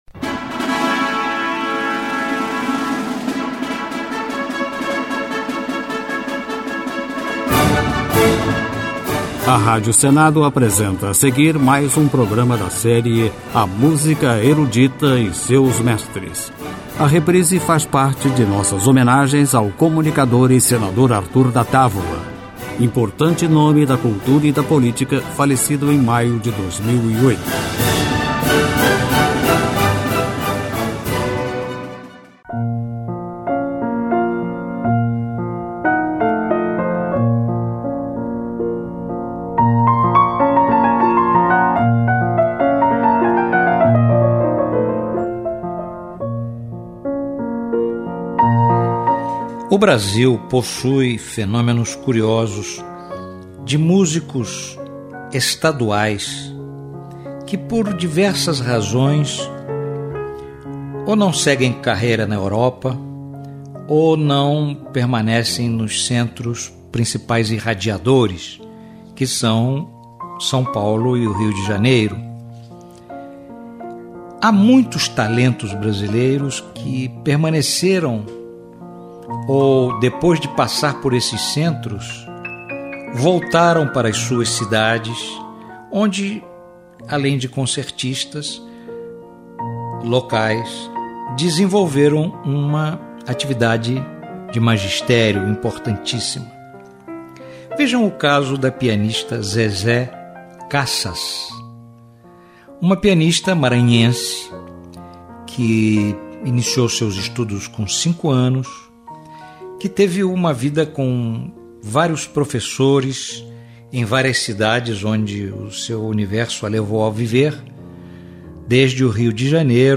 Música Erudita
Pianistas brasileiros